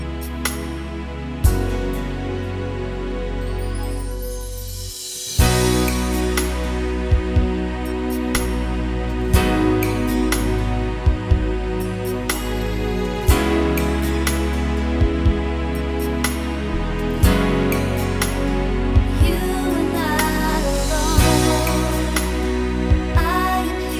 With Full Backing Vocals Pop (2000s) 4:09 Buy £1.50